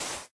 dig / sand3